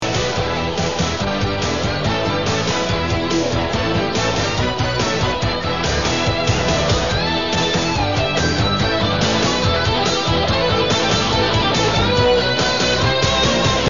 Encore un petit générique, en audio ce coup-ci